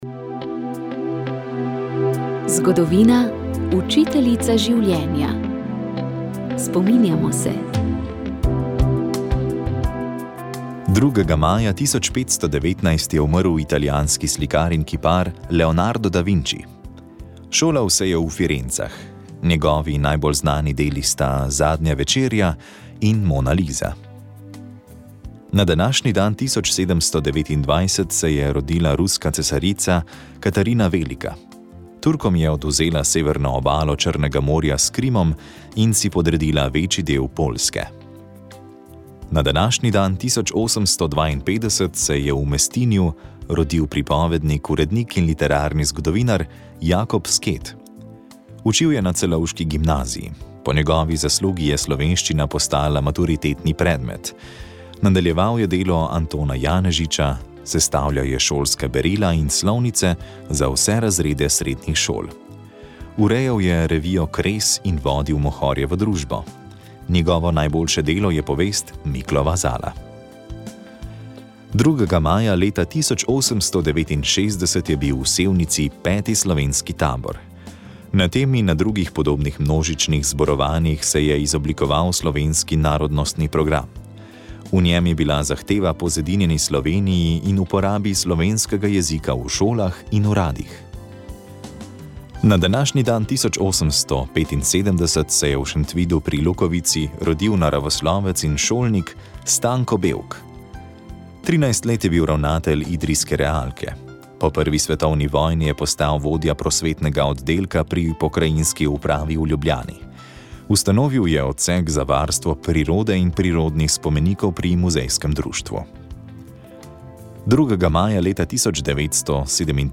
Sv. maša iz cerkve sv. Marka na Markovcu v Kopru 30. 4.